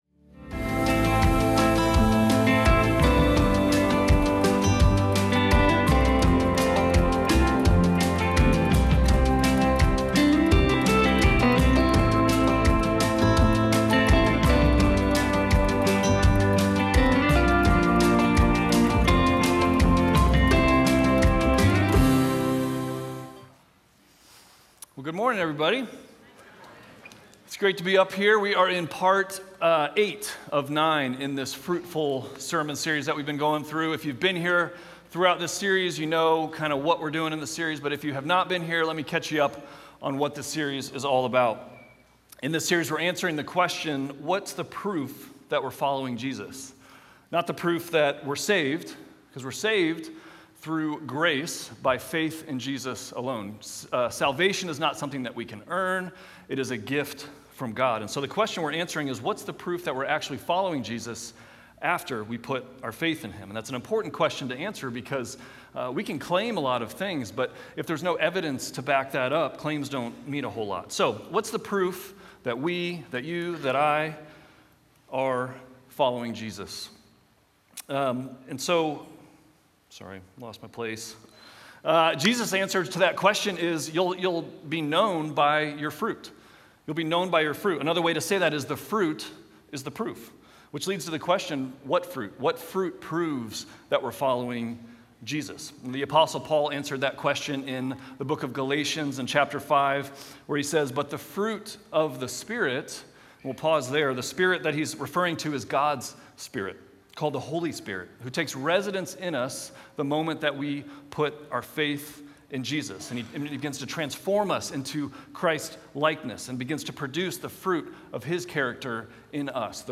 Sunday Sermons FruitFULL, Week 8: "Gentleness" Mar 15 2026 | 00:36:53 1x 00:00 / 00:36:53 Subscribe Share Apple Podcasts Spotify Overcast RSS Feed Share Link Embed